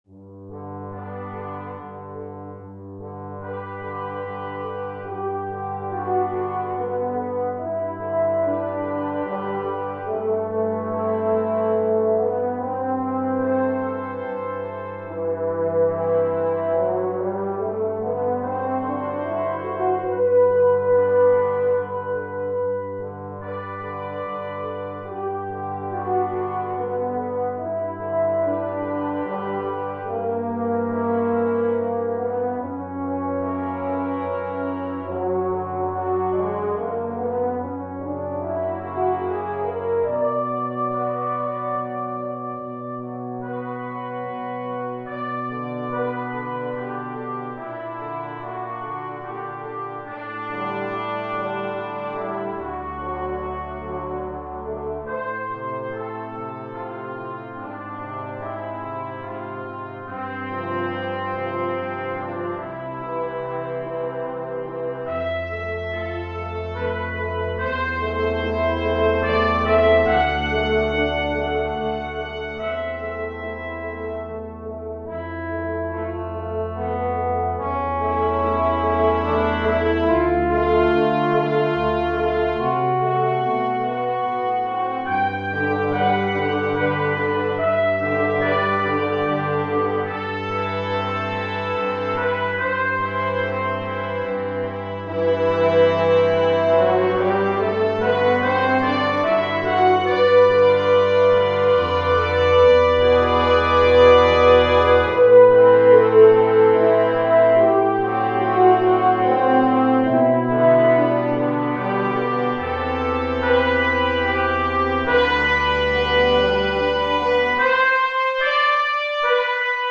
【編成】金管五重奏 (2 Trumpet, Horn, Trombone, Tuba)